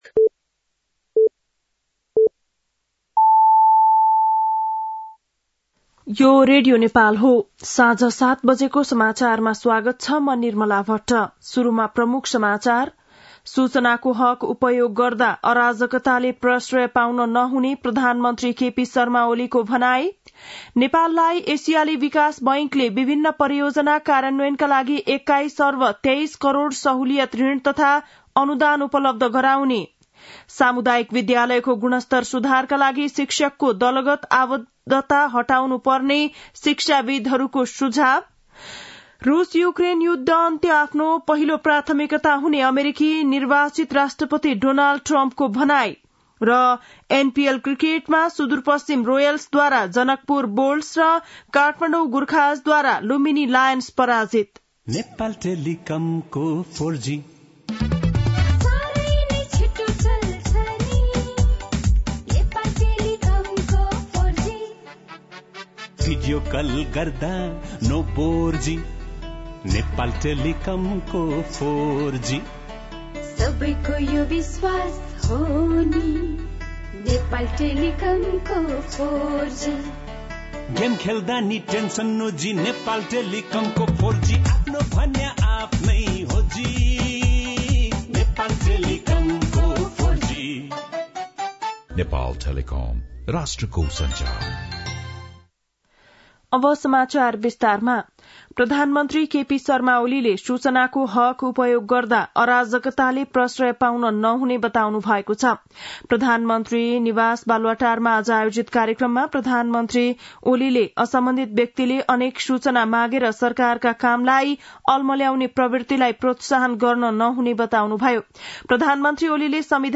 बेलुकी ७ बजेको नेपाली समाचार : २७ मंसिर , २०८१